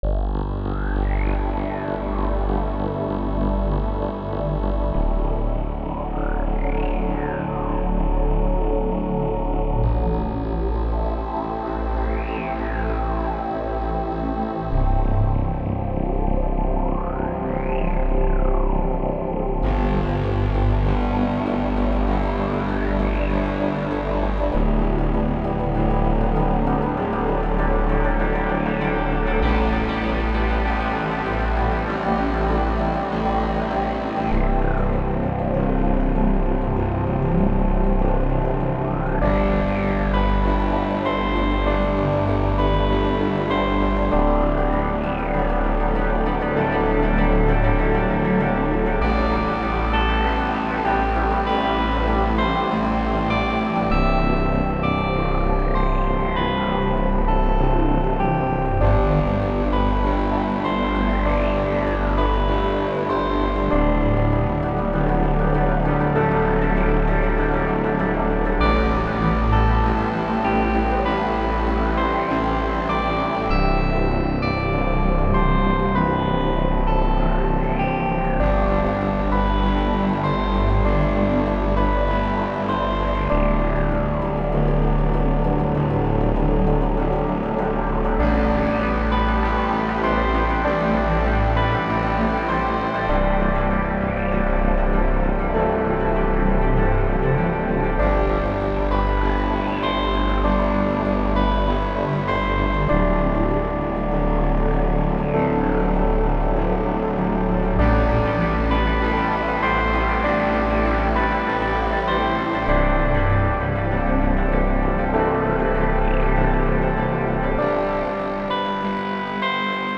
So issued me a challenge to create a 98BPM track with no drums only using the Sytrus plugin found in FLstudio. When drums are excluded my mind focuses entirely on Chord mixing.